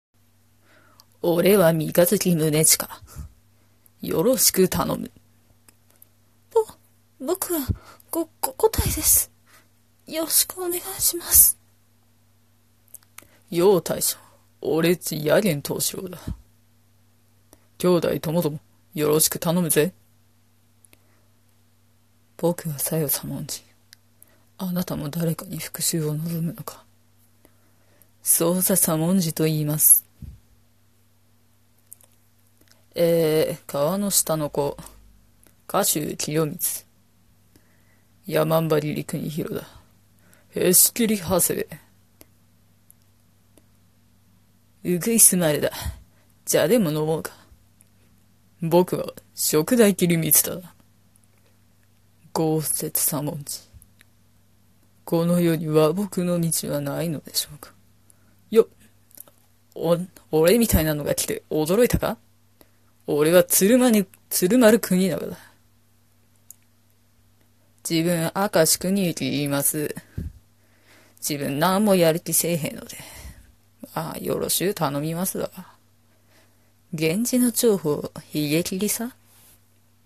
刀剣男士14振り声真似練習